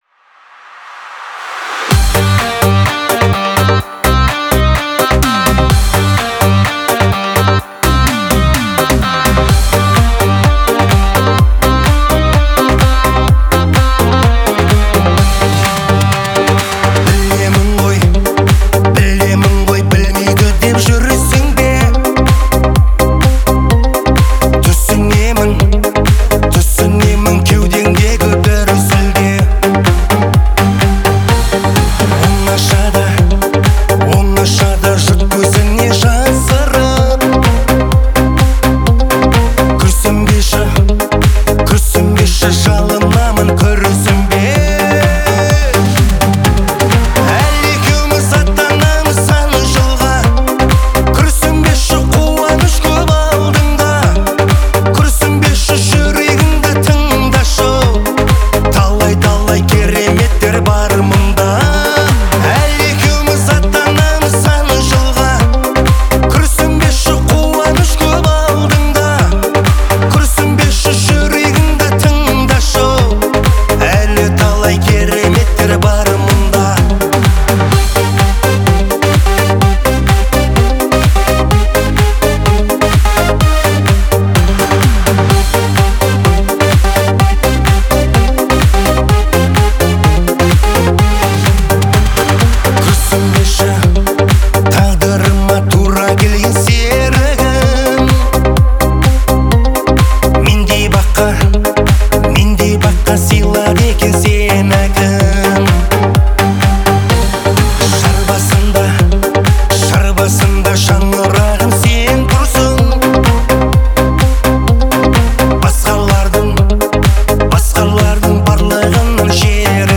отличается мелодичностью и душевностью